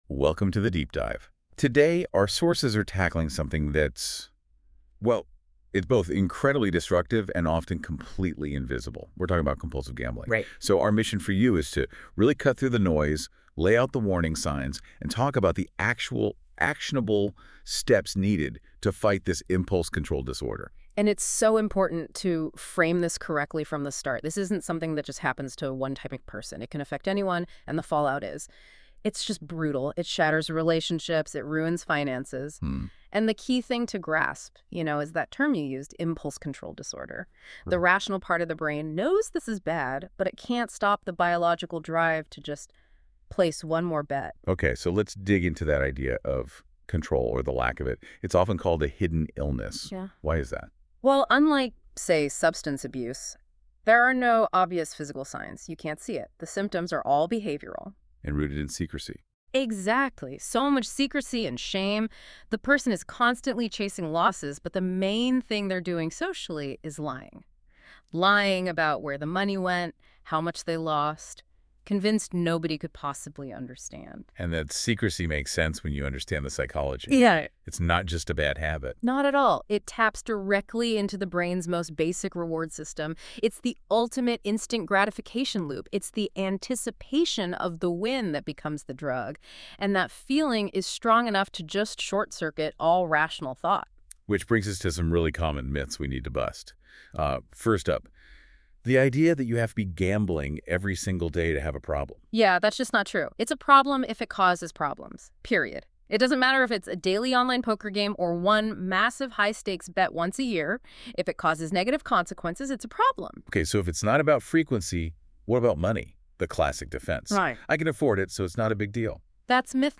A key deliverable shared on the website is a generated podcast. The gambling addiction podcast talks about different styles of gambling, including modern ways such as online gambling that provides “instant 24 access”. The participant used AI to develop a conversational-style podcast that focuses on informing and persuading listeners.
ai-podcast-2.mp3